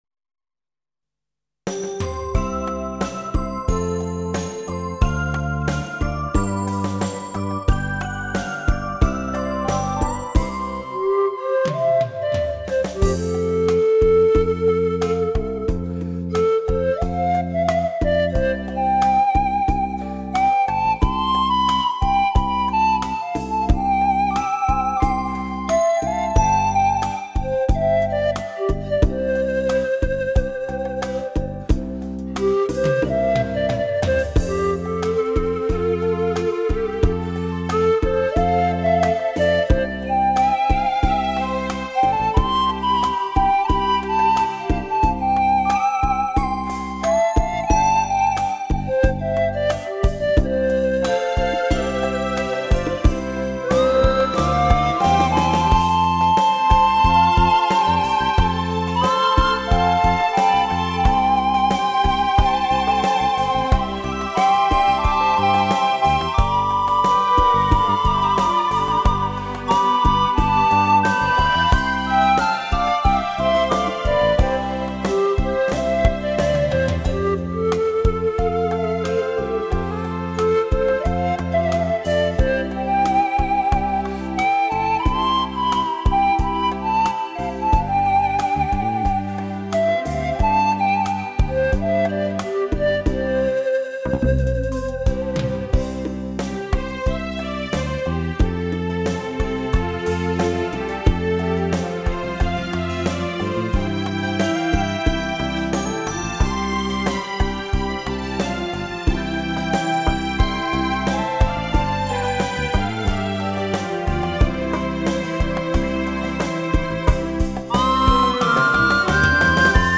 排箫